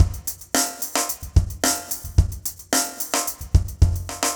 RemixedDrums_110BPM_36.wav